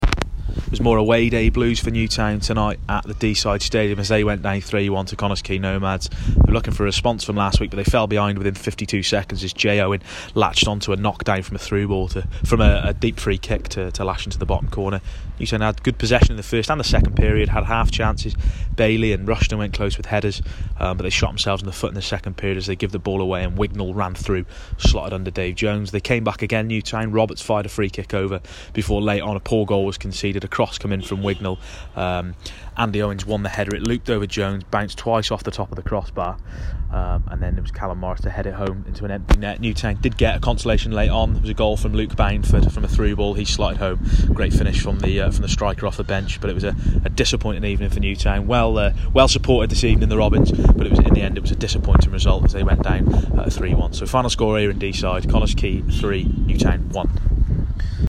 AUDIO REPORT - Nomads 3-1 Robins